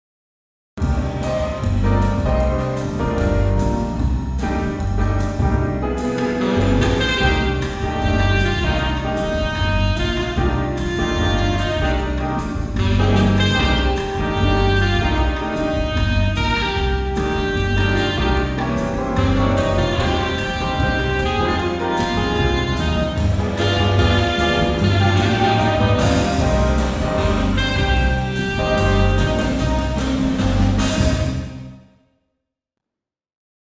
Room Effect Samples
Freeverb3_VST ProG Reverb
Preset - Medium Room Bright
Room_ProG_Medium_Room_Bright.mp3